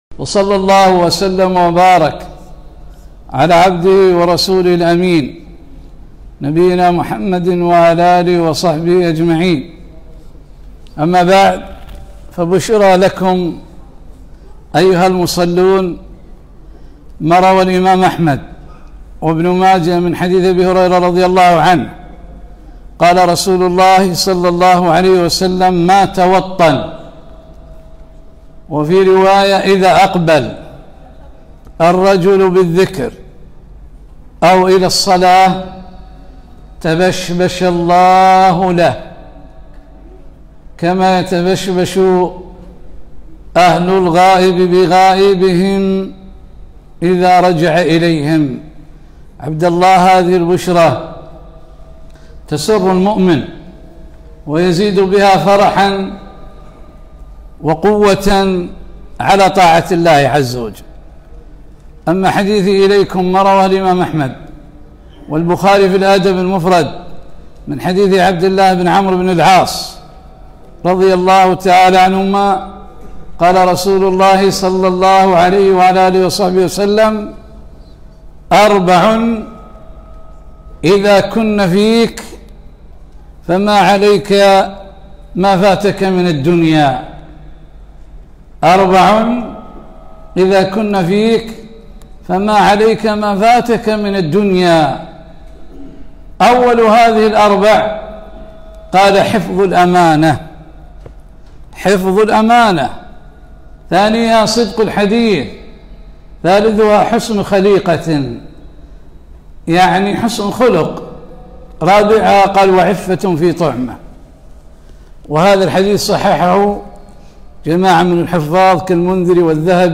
محاضرة - أربع إذا كن فيك فلا عليك ما فاتك من الدنيا